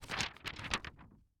sfx-paper-movement.074435d8c81ed2ce3d84.mp3